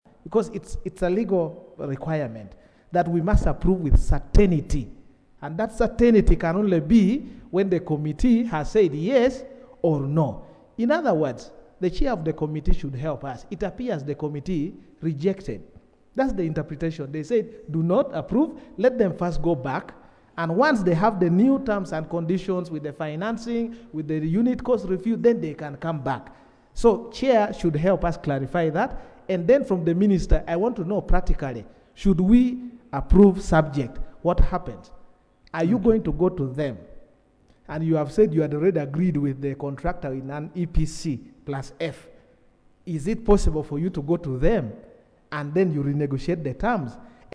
Hon. Jonathan Odur (UPC, Erute County South) argued that approving the loan without addressing the committee’s concerns could lead to a resolution misaligned with Parliament’s intent, urging a review before approval.